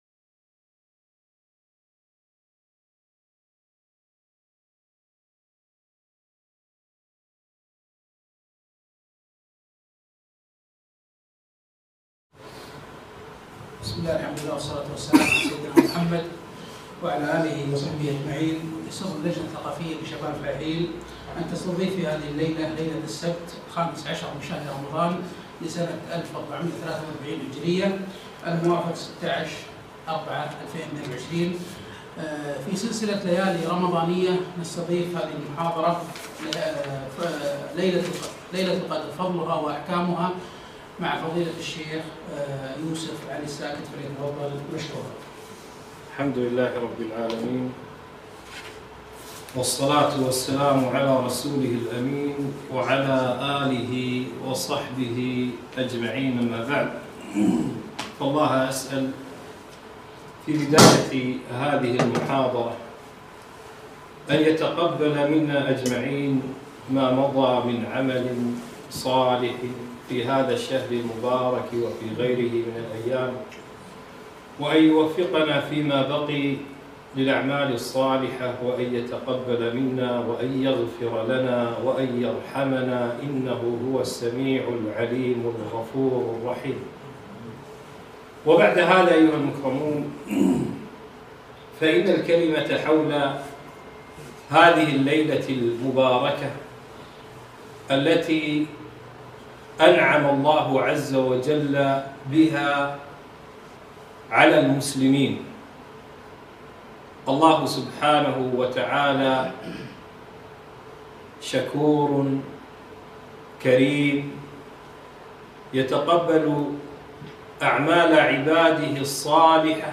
محاضرة - ليلة القدر فضلها وأحكامها